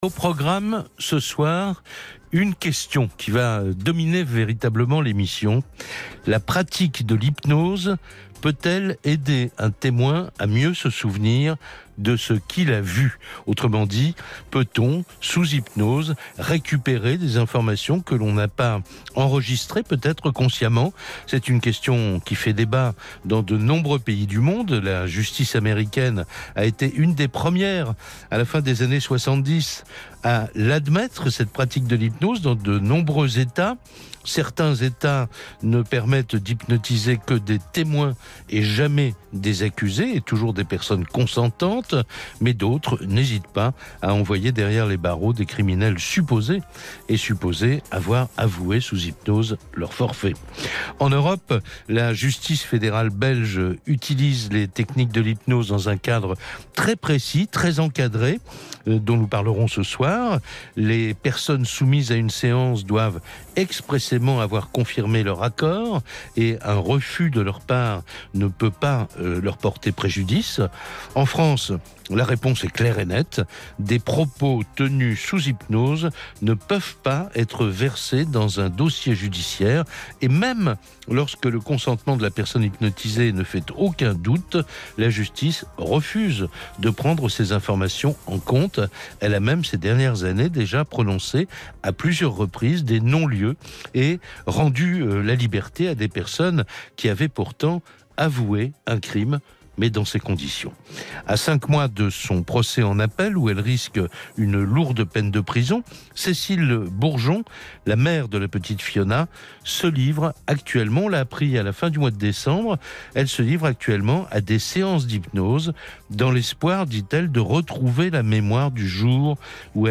L’émission L’heure du crime, animée par Jacques Pradel du 06 janvier 2019 sur RTL